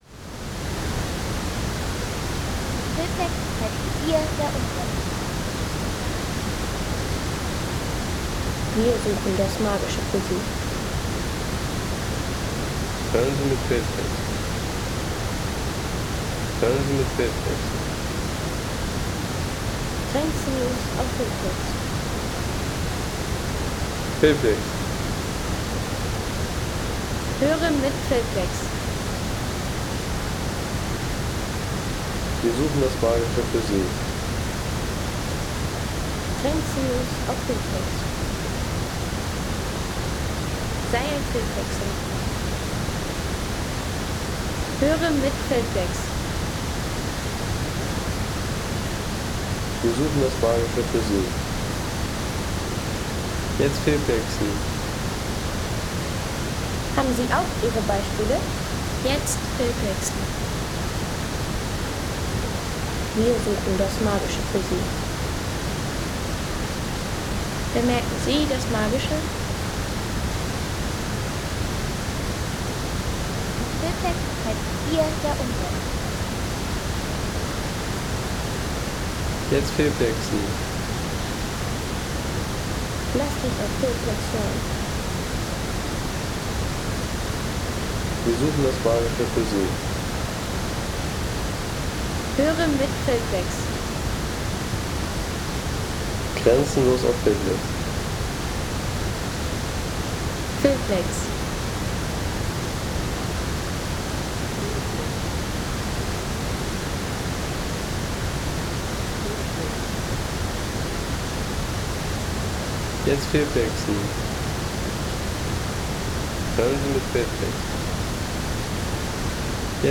Langfossen – Der mächtige Wasserfall am Åkrafjord
Landschaft - Wasserfälle
Naturgewalt am Langfossen – Der mächtige Wasserfall am Åkrafjord.